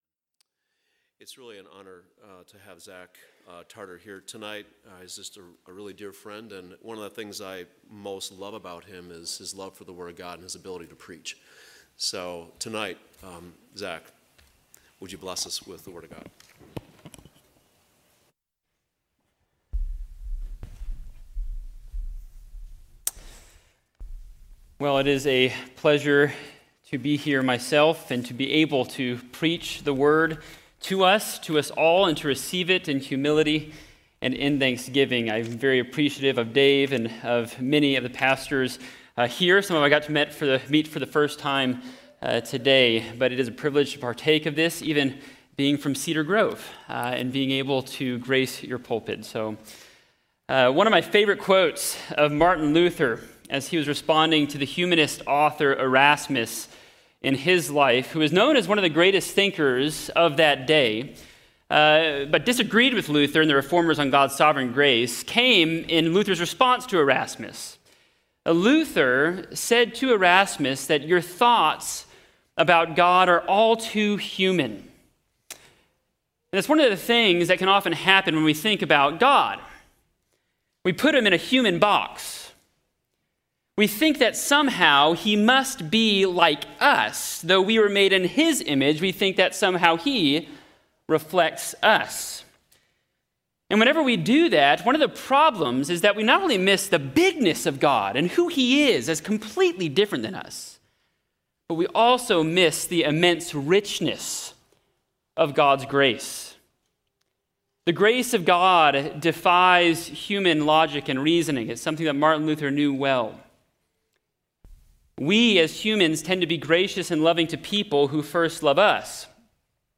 Community Reformation Day Service | Ephesians 1: 3-14